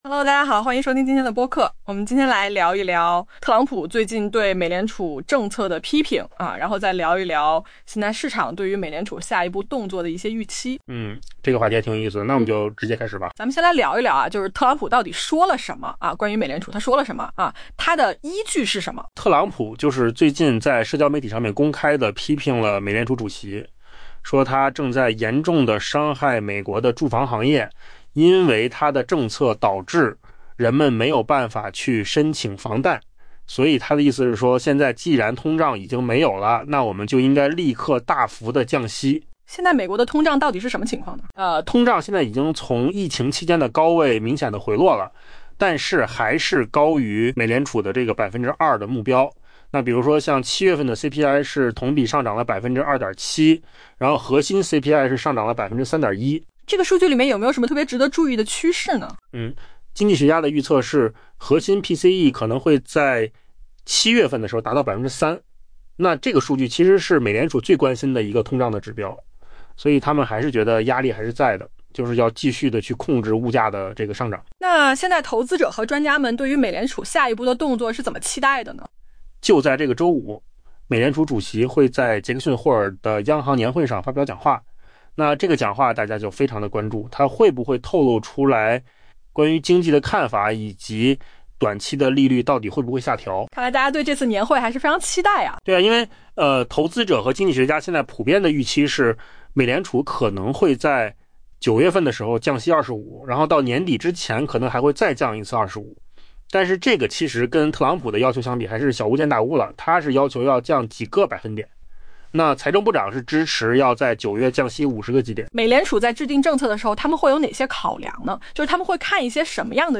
AI播客：换个方式听新闻